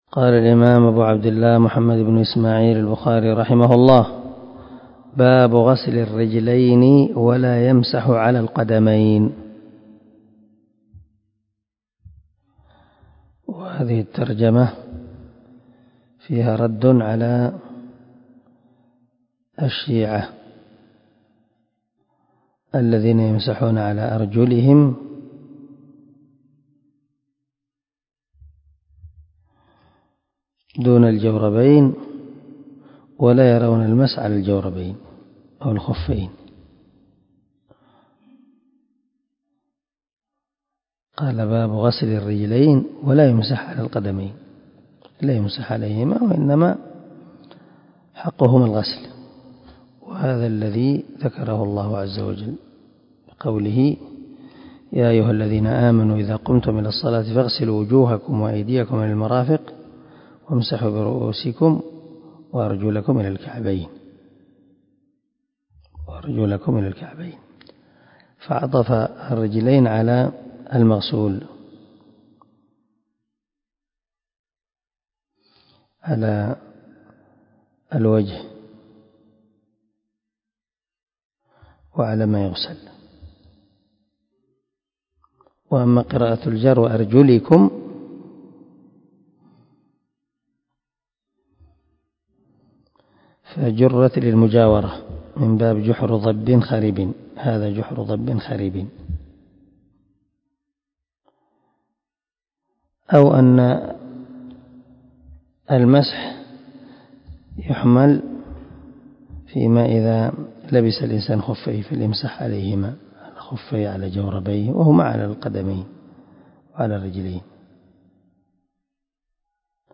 148الدرس 24 من شرح كتاب الوضوء حديث رقم ( 163 ) من صحيح البخاري